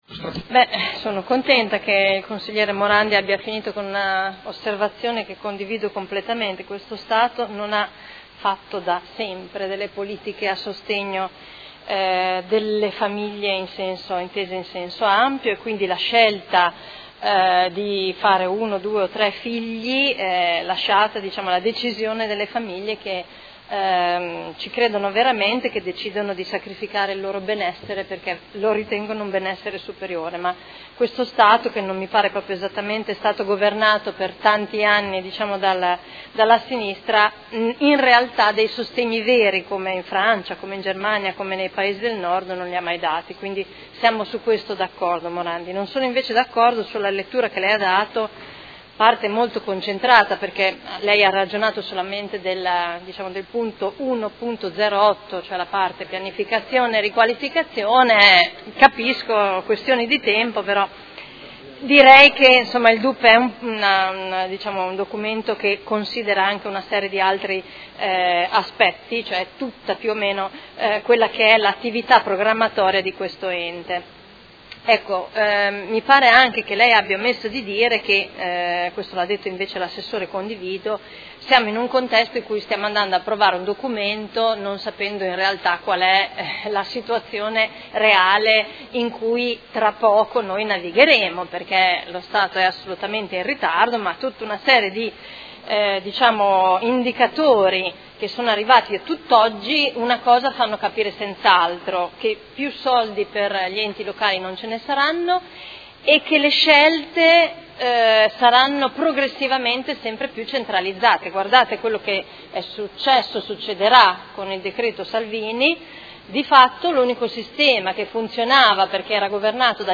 Seduta del 27/09/2018. Dibattito su proposta di deliberazione: Documento Unico di Programmazione 2019-2021 – Approvazione